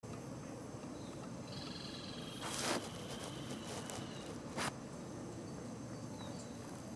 Rufous-sided Crake (Laterallus melanophaius)
Life Stage: Adult
Location or protected area: Reserva Ecológica Costanera Sur (RECS)
Condition: Wild
Certainty: Recorded vocal